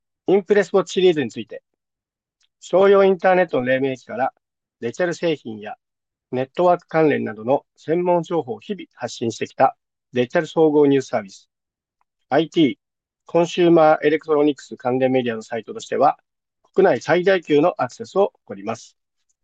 マイクの性能も本体録音とビデオ会議「Zoom」での録音を試しましたが、本体録音だと音質の差は感じられるものの、圧縮音源となるビデオ会議ではその差もさほど大きくありませんでした。